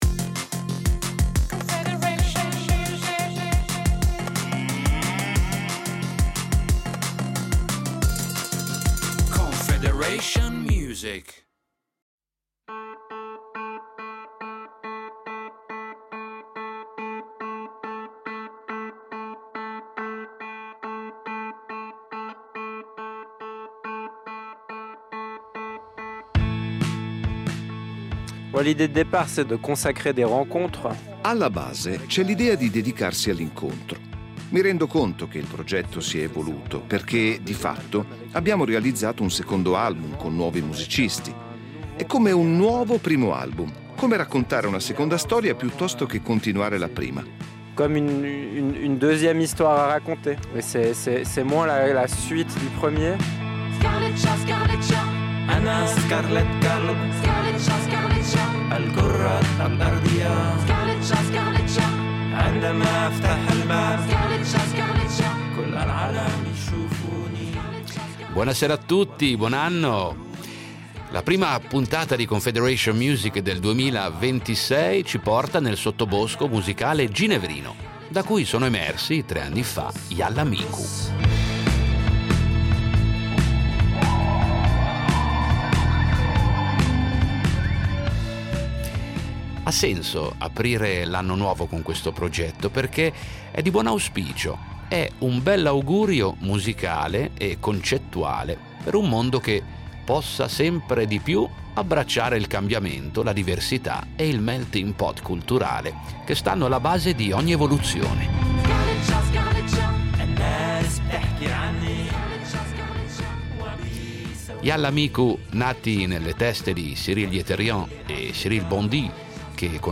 Musica world